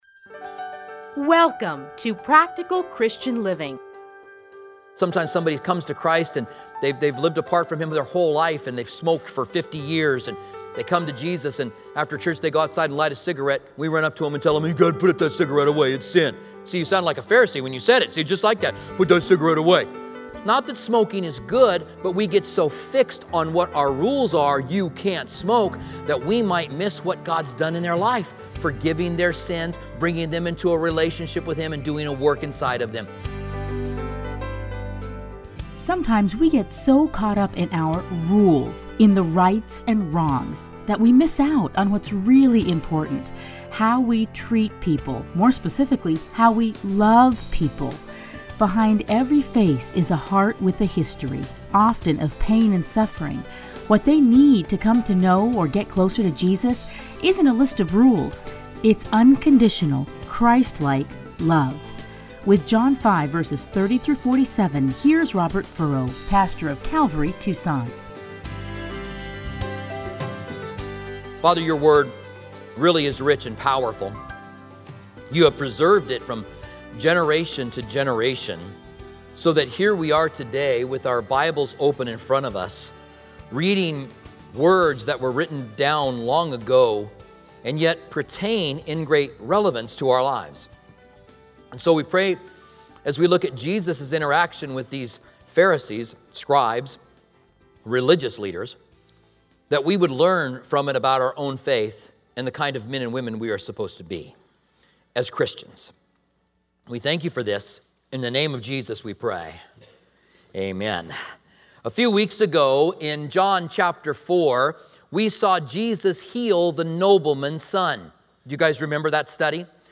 edited into 30-minute radio programs titled Practical Christian Living